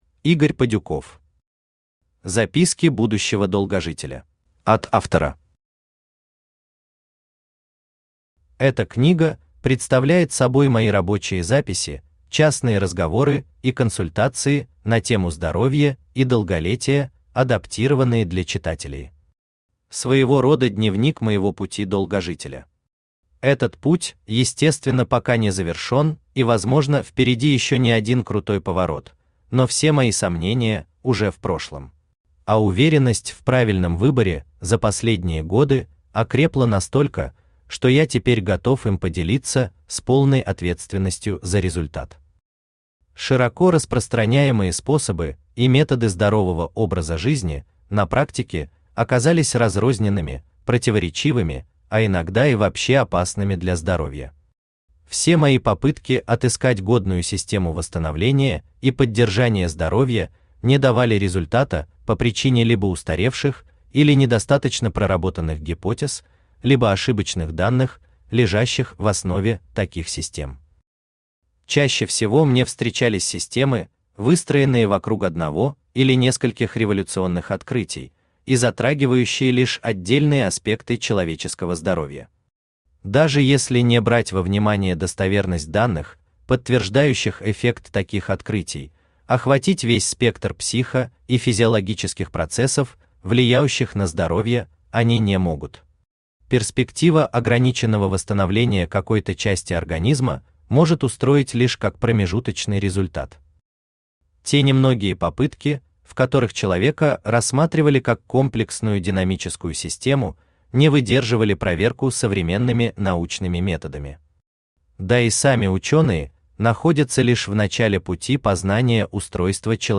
Аудиокнига Записки будущего долгожителя | Библиотека аудиокниг
Aудиокнига Записки будущего долгожителя Автор Игорь Юрьевич Падюков Читает аудиокнигу Авточтец ЛитРес.